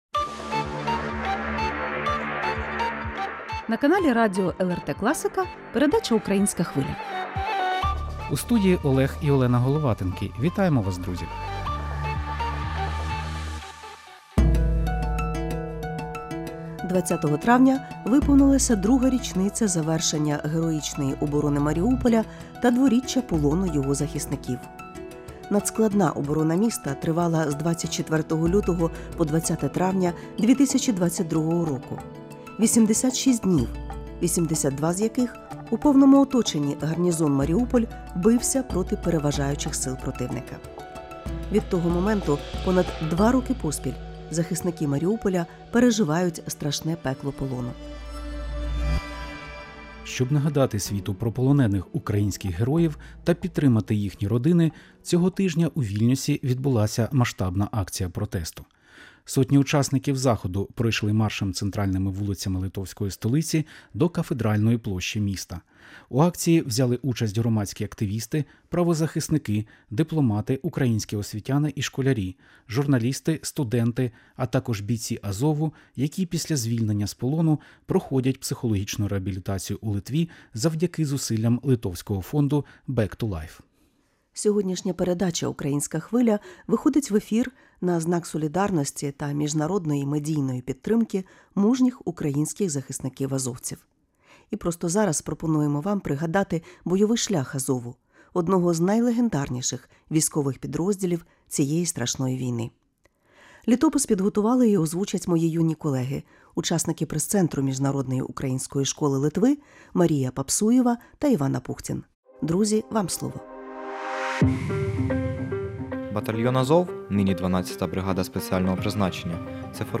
Репортаж з мітингу в столиці Литви, ексклюзивні інтерв'ю азовців, літопис легендарного підрозділу, молитва Азову та пісня “Азов - сталь” - у спеціальному випуску передачі “Українська Хвиля”.